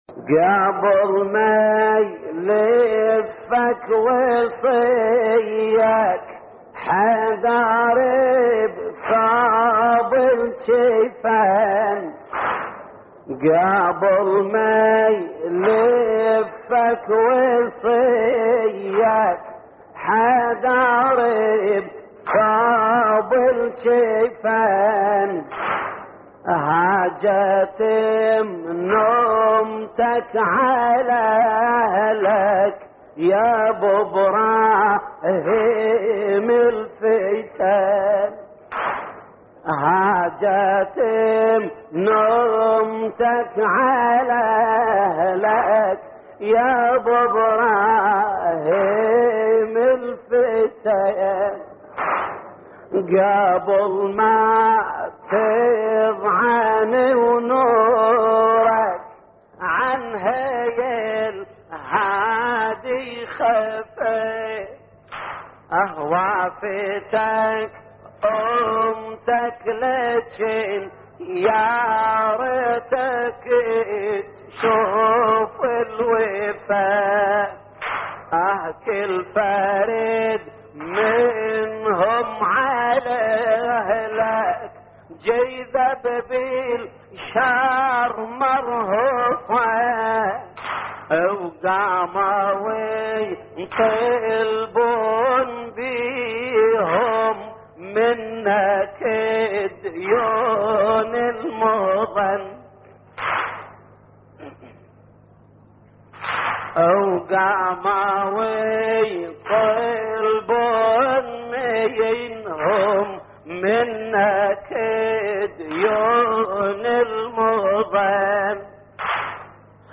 تحميل : قبل ما يلفك وصيك حيدر بثوب الكفن / الرادود جليل الكربلائي / اللطميات الحسينية / موقع يا حسين